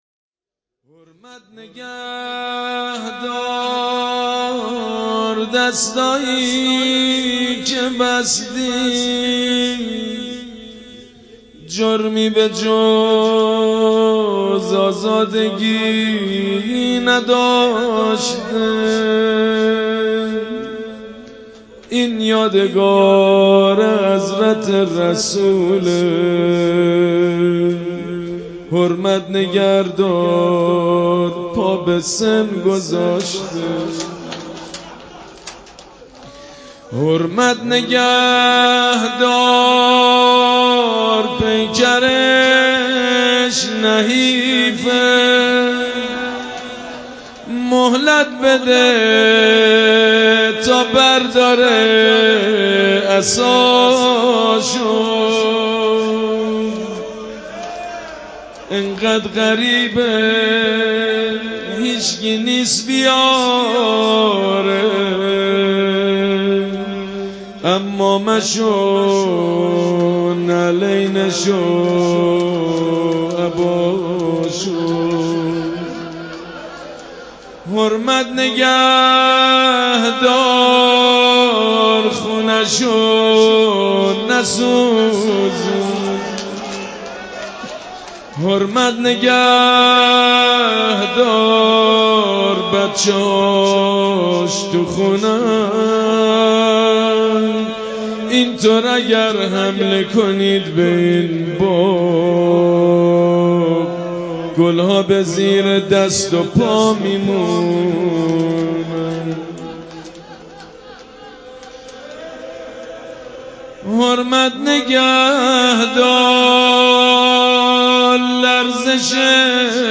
روضه و توسل ویژه شهادت امام جعفرصادق(ع)، سیدمجید بنی فاطمه -(حرمت نگه دار دستایی که بستی..)
مداح سیدمجیدبنی-فاطمه